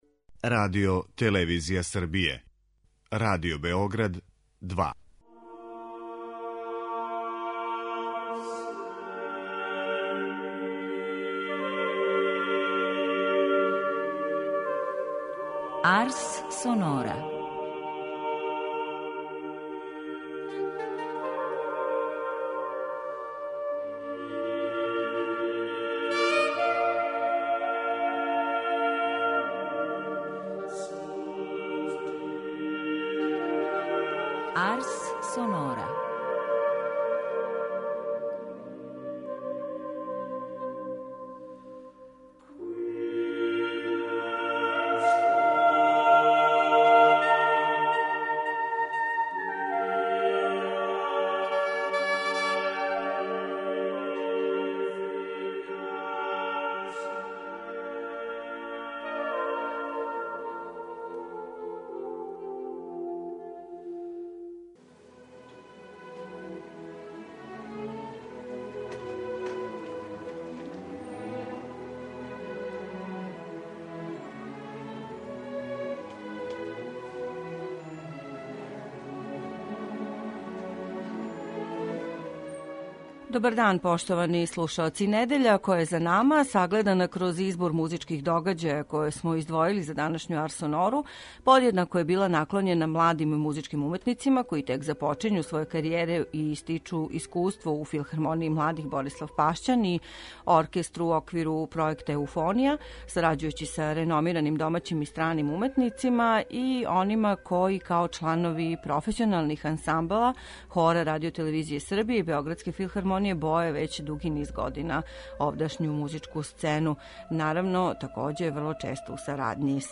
Избор музичких догађаја које смо издвојили за данашњу Арс сонору чине четири концерта којa су београдској публици пружила прилику да чују два омладинска оркестра - Филхармонију Борислав Пашћан и ансамбл формиран у оквиру пројекта Еуфонија, као и два угледна домаћа професионална ансамбла. Хор РТС је извео дела Баха и Монтевердија, а Београдска филхармонија трећи чин опере Зигфрид Рихарда Вагнера.